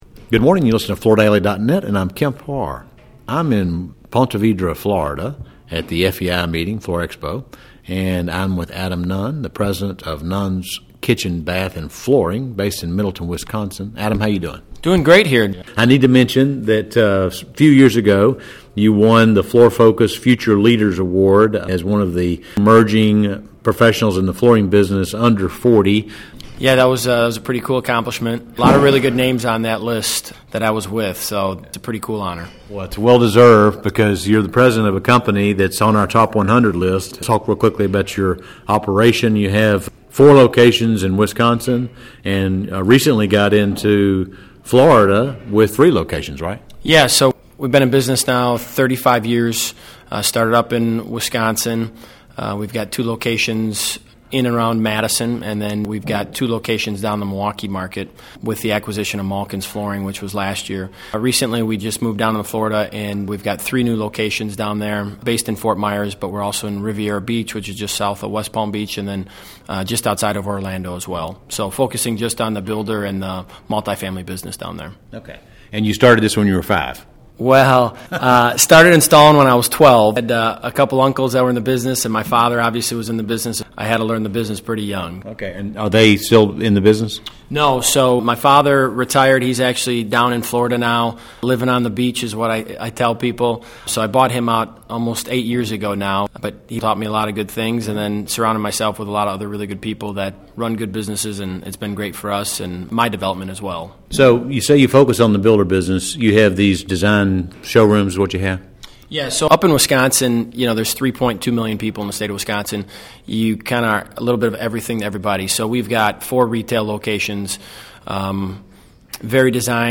Listen to the interview, taped at the FEI Group annual meeting, for more details.